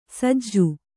♪ sajju